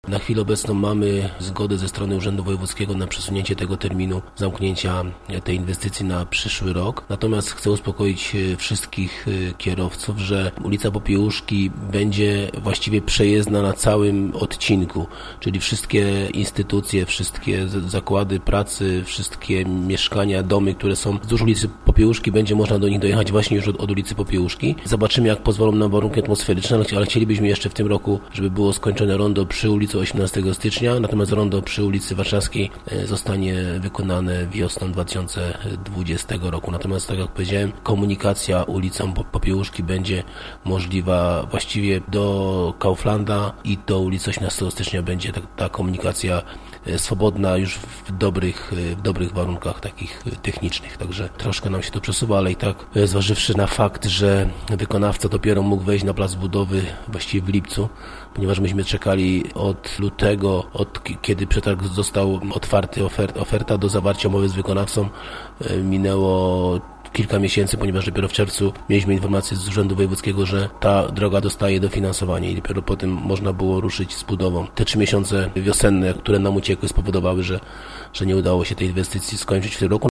Spowodowane jest to, jak tłumaczy nam w rozmowie burmistrz Wielunia, Paweł Okrasa, między innymi kolizjami energetycznymi i budową nowej nitki wodociągów.